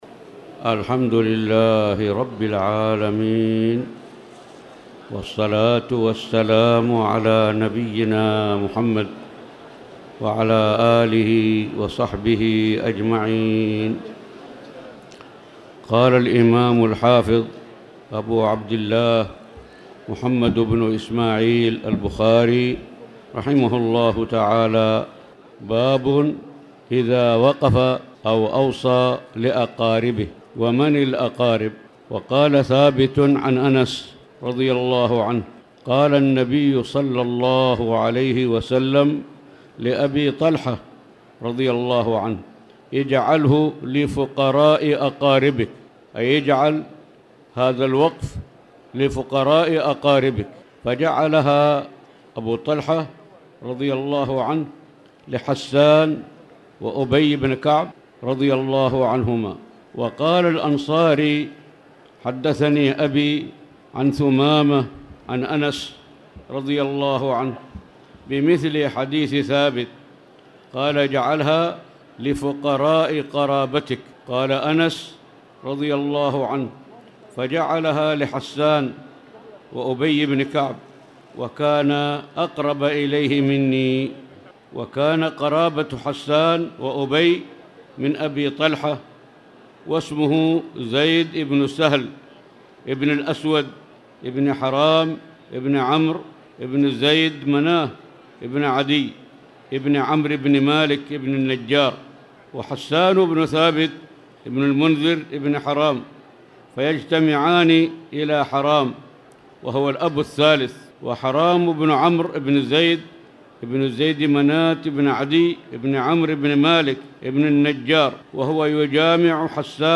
تاريخ النشر ١٩ رمضان ١٤٣٨ هـ المكان: المسجد الحرام الشيخ